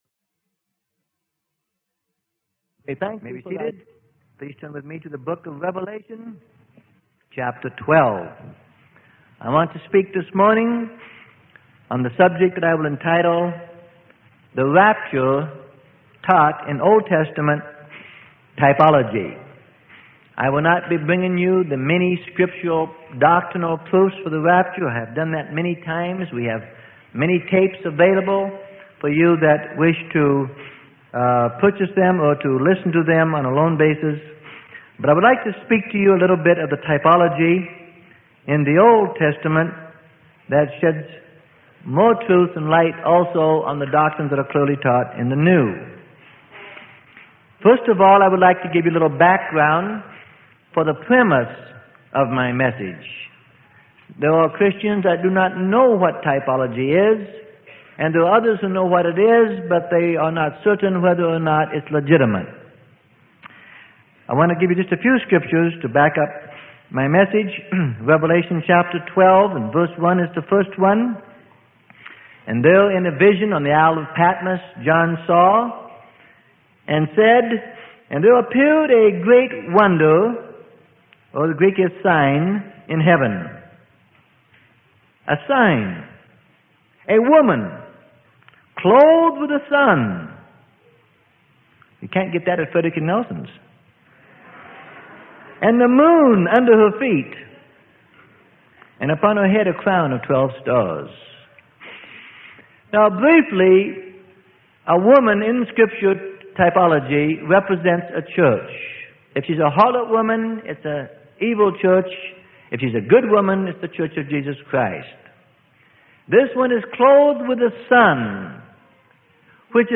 Sermon: The Rapture Taught in Old Testament Typology - Freely Given Online Library